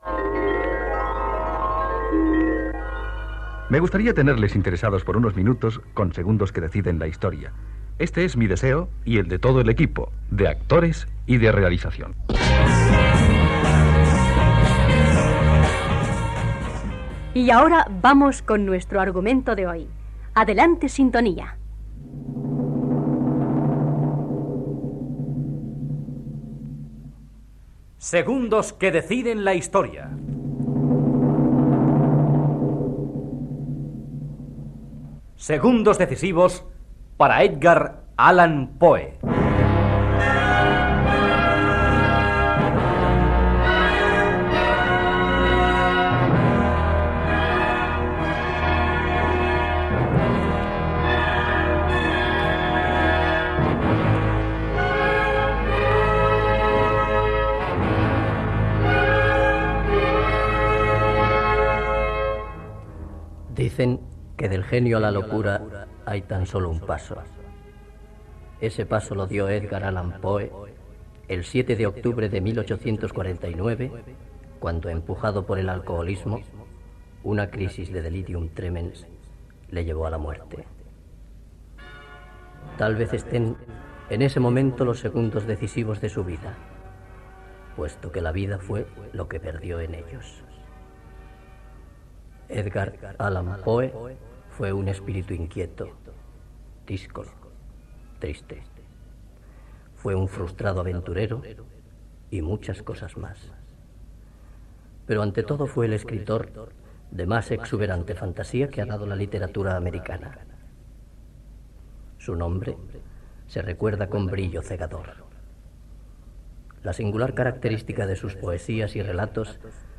Espai dedicat a Edgard Allan Poe. Careta del programa, perfil biogràfic de l'esciptor nord-americà. El personatge Egeo parla de la seva vida i de Berenice.